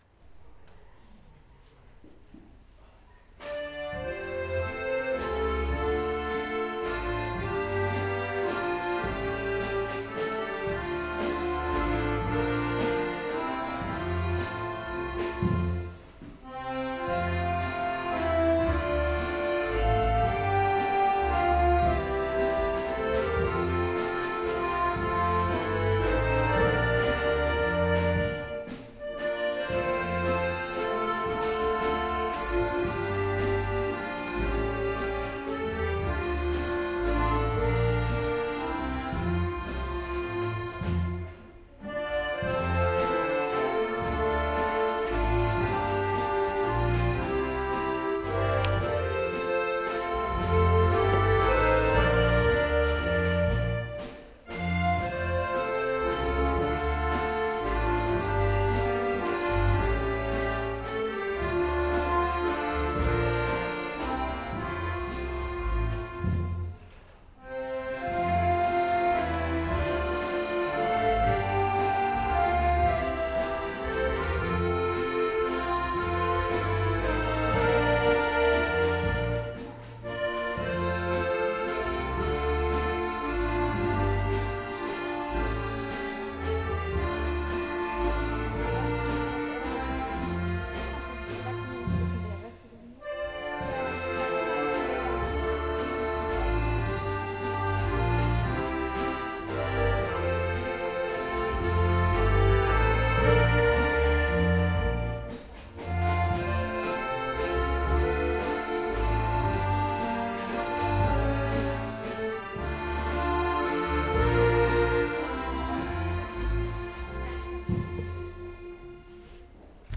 Konzerte in Graz, Wagrain, Feldkirchen, Kumberg, seit 2017
Vom Kinderorchester: